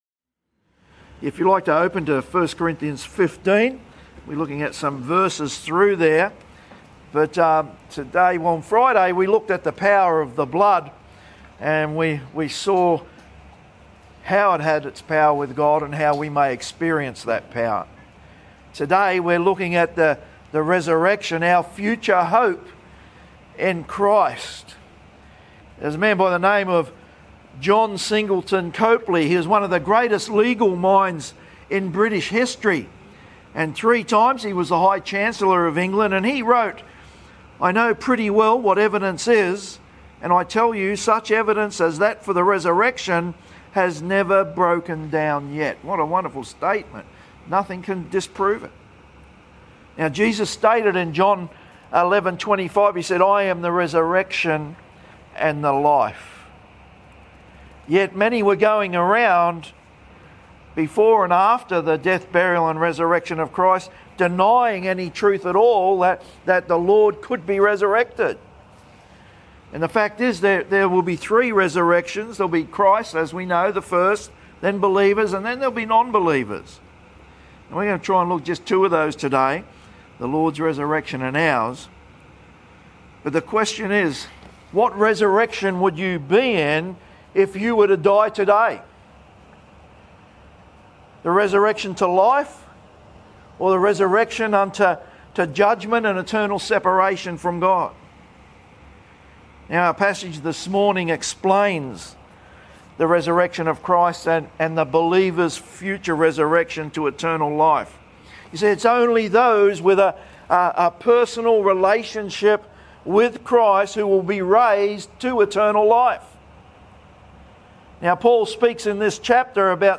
Easter Sunday sermon